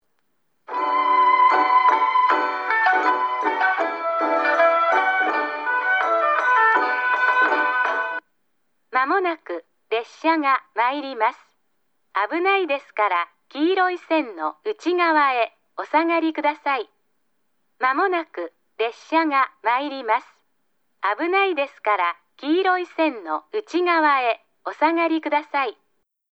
下り列車接近放送　女声   放送は、九州カンノ型C　です。2019年3月より接近メロディが導入され延岡市の郷土芸能「ばんば踊り」が流れます。メロディ後に放送が2回流れます。
スピーカーは「TOA　ラッパ型」ですが、駅員用と自動放送用で分離しています。